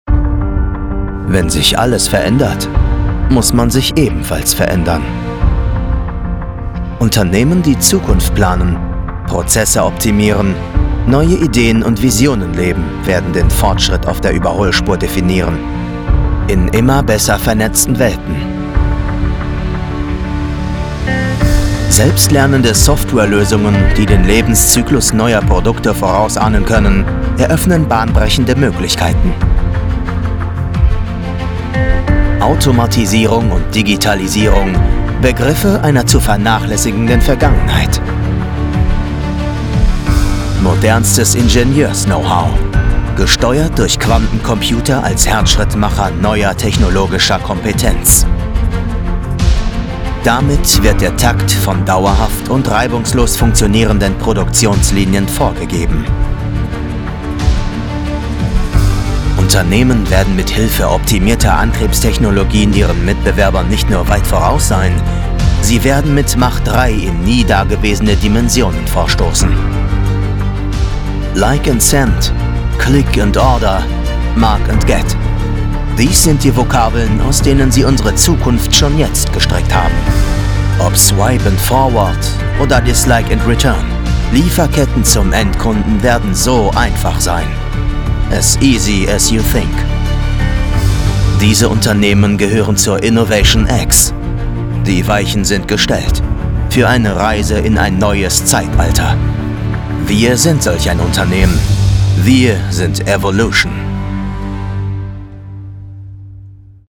Imagefilm IT - Company (Evolution)
Young Adult
Acoustics: Vocal booth including Caruso Iso Bond 10cm, Basotect for acoustic quality.